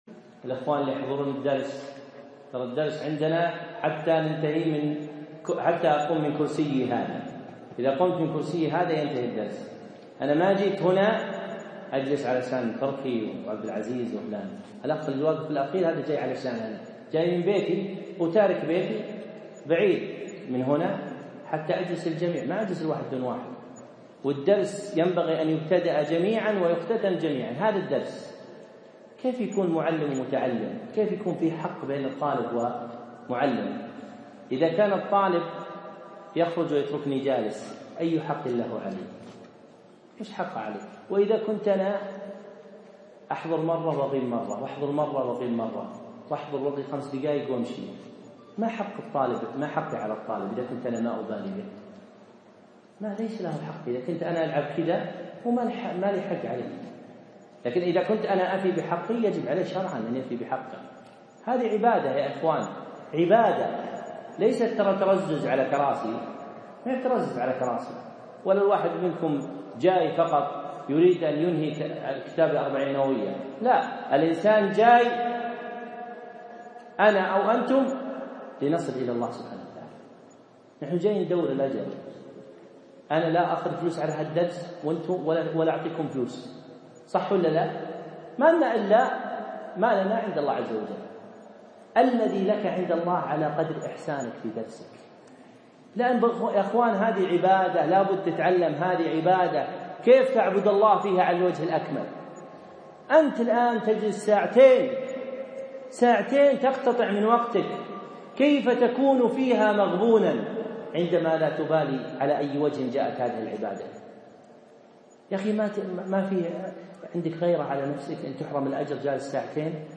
موعظة نافعة في أخذ العلم